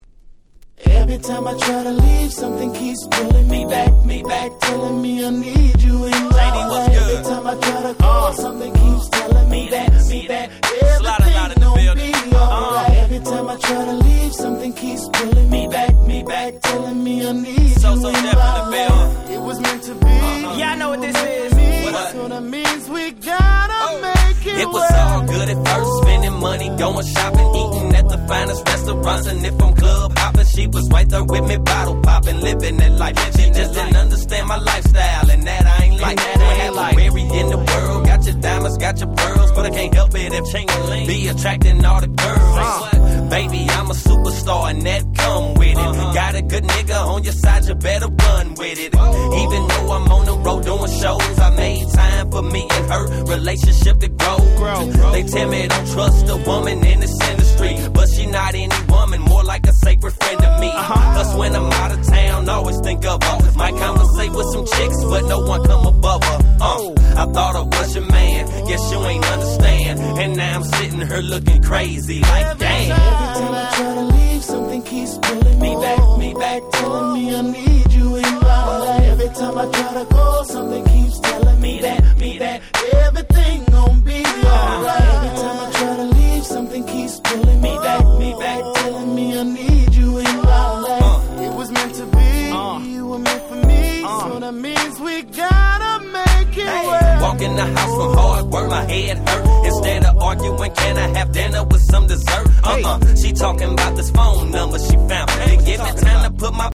06' Smash Hit Hip Hop !!